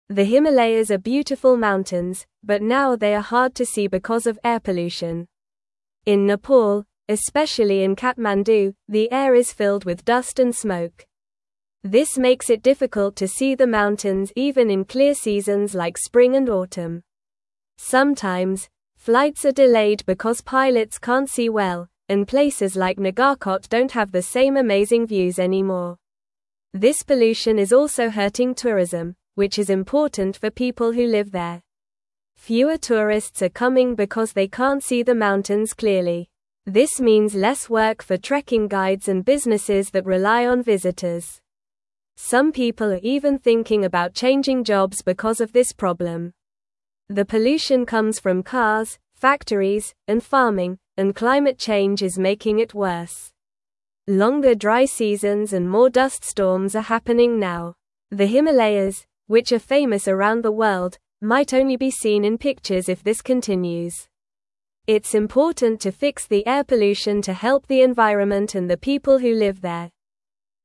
Normal
English-Newsroom-Lower-Intermediate-NORMAL-Reading-Dirty-Air-Hides-Beautiful-Himalayas-from-Everyone.mp3